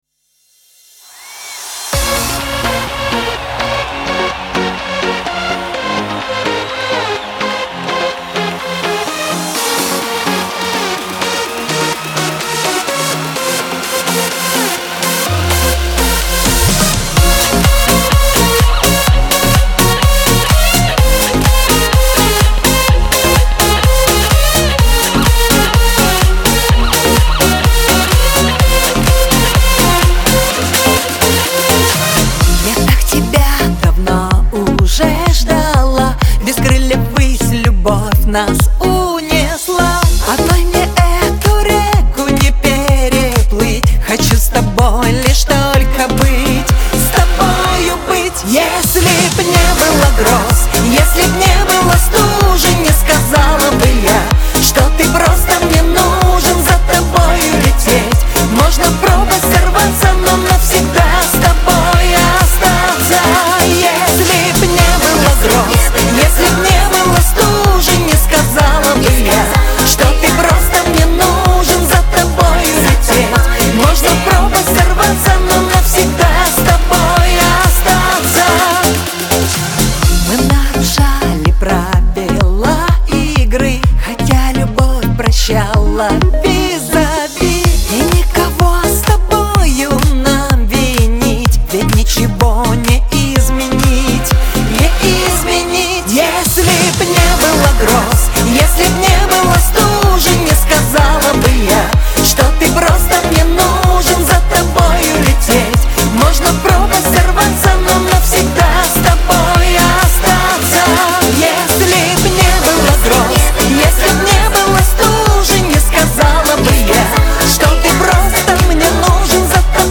pop , диско , эстрада